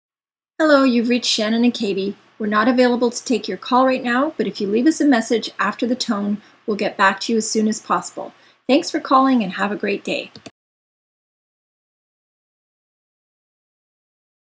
Telephone Voice Mail Messages
VoiceMail.m4a